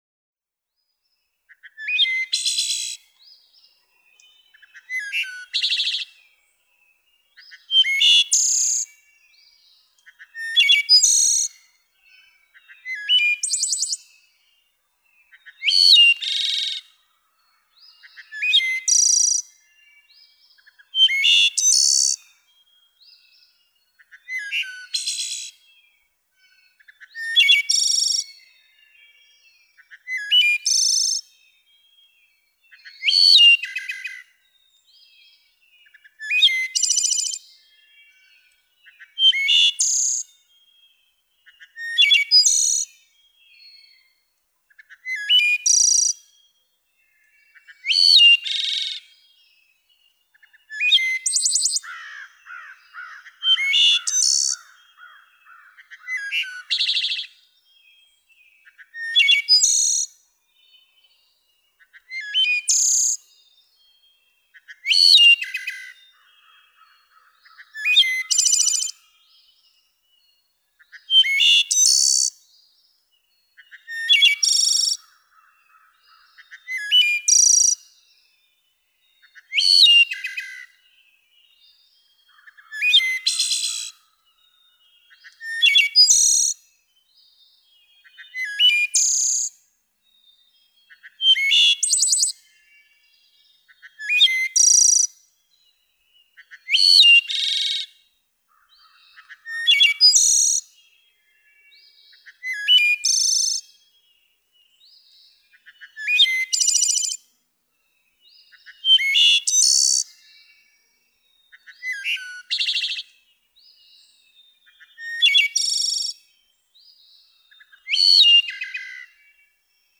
♫116. Wood thrush normal singing, 43 songs in a little over two minutes. May 3, 2002. Amherst, Massachusetts. (2:06)
116_Wood_Thrush.mp3